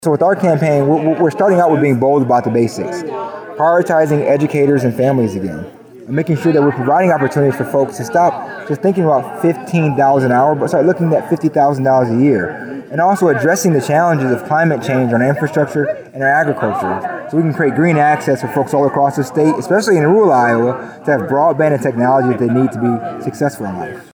Ras Smith is a state representative from Waterloo and spoke at Thursday’s Humboldt County Democrats Family picnic in Dakota City. Smith says his campaign is about working together for solutions for the people of Iowa.